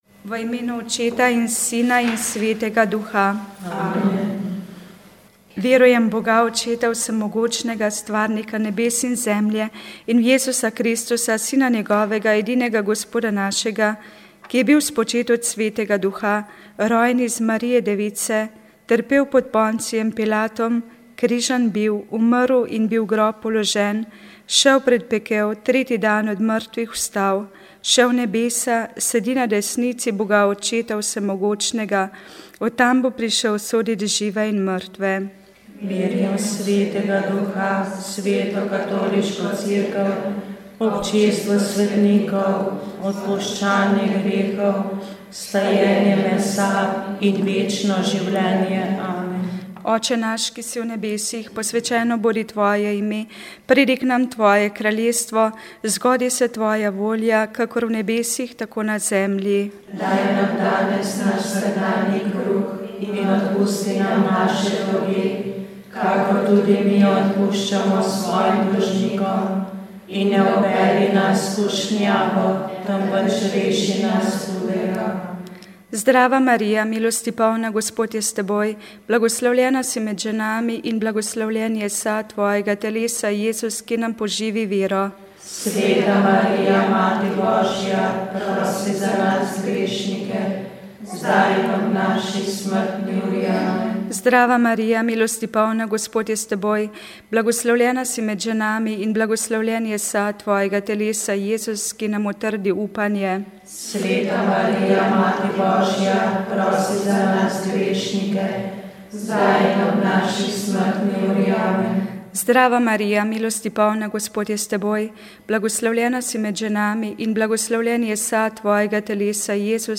Rožni venec
Molile so redovnice - Hčere krščanske ljubezni (Usmiljenke).